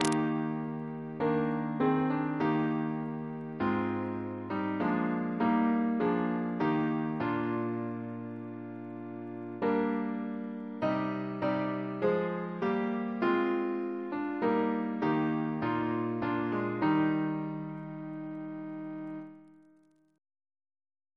Double chant in E♭ Composer: Sir John Goss (1800-1880), Composer to the Chapel Royal, Organist of St. Paul's Cathedral Reference psalters: ACB: 106; ACP: 12 80; CWP: 180; H1982: S218; OCB: 138; PP/SNCB: 86